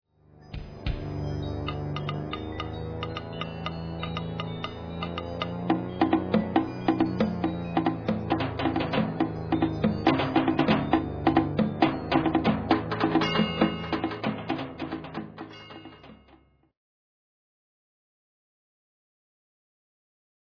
Suspenseful.